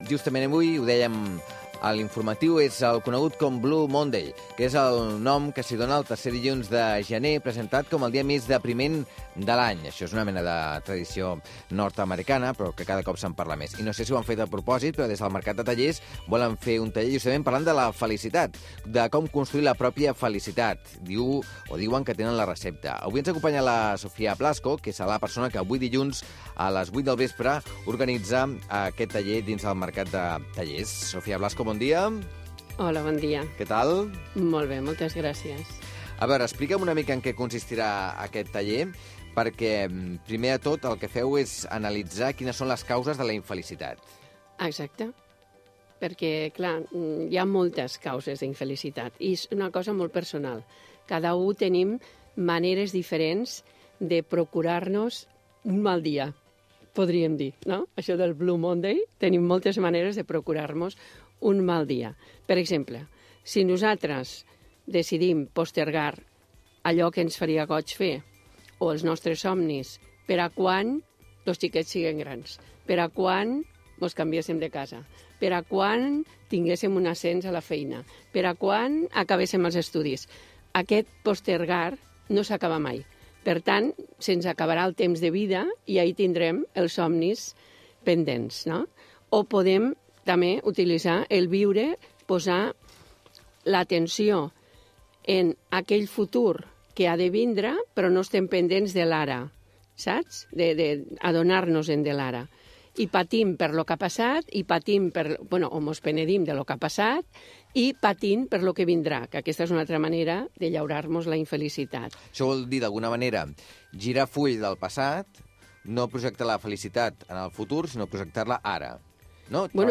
Al programa ‘Això no té nom‘, de Ràdio Ciutat de Badalona